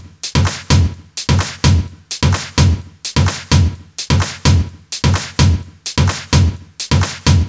VDE 128BPM Notice Drums 3.wav